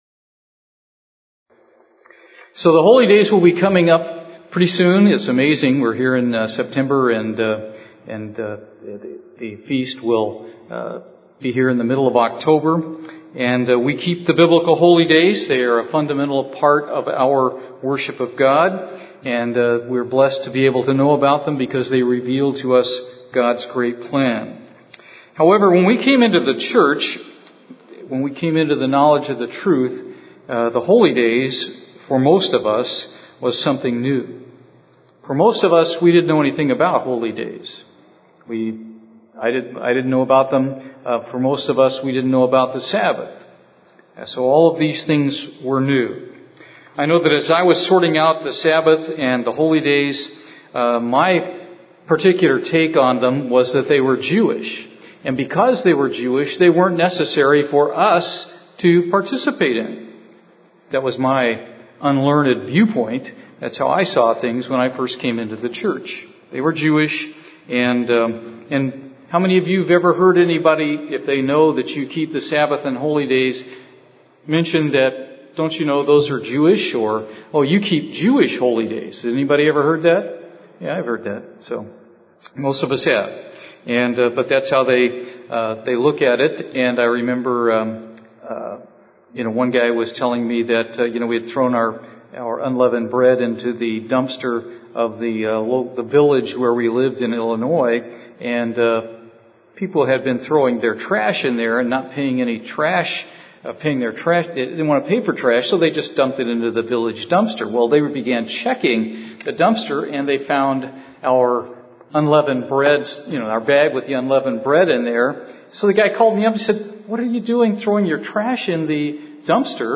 Those who do not believe Christians are required to keep the holy days often point to verses in john where he uses terms like "a feast of the jews" and claim this proves the feasts are only to be kept by the Jews. In this sermon